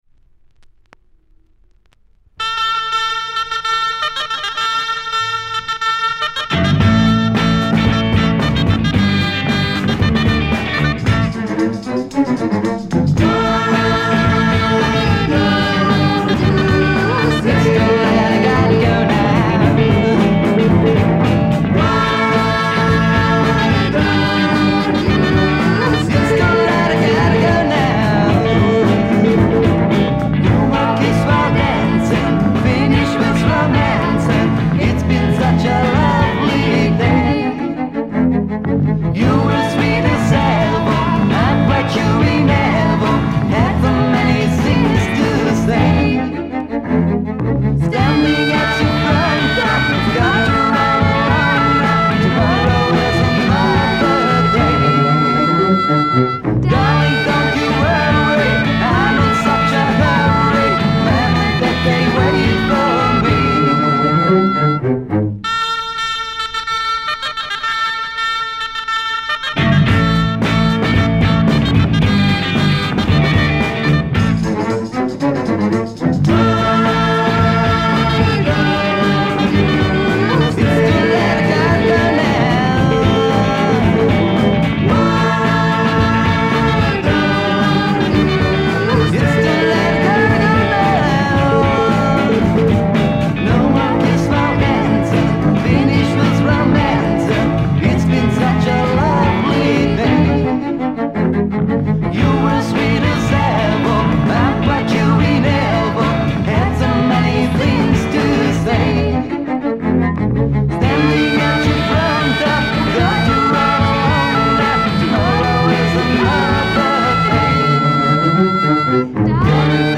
Voilà c'est inconnu, c'est Allemand de 1967 de Franfurt :